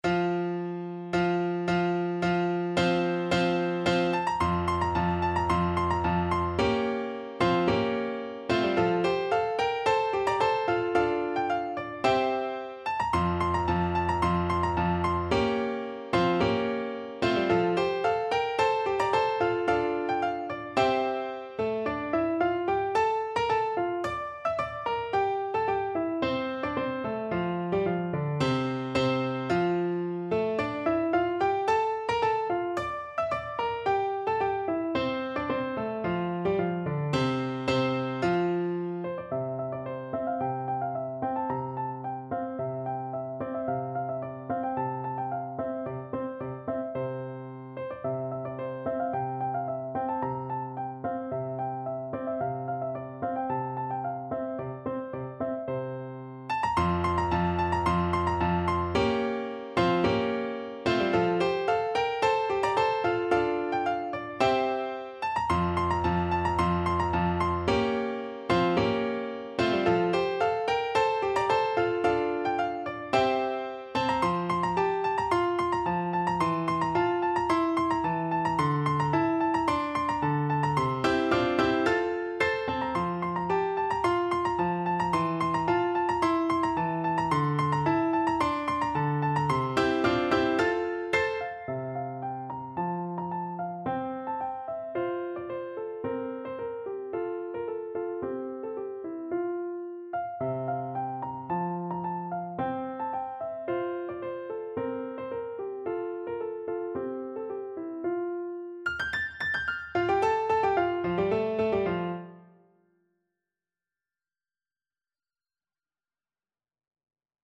Play (or use space bar on your keyboard) Pause Music Playalong - Piano Accompaniment Playalong Band Accompaniment not yet available transpose reset tempo print settings full screen
= 110 Allegro di molto (View more music marked Allegro)
F major (Sounding Pitch) G major (Clarinet in Bb) (View more F major Music for Clarinet )
2/2 (View more 2/2 Music)
Classical (View more Classical Clarinet Music)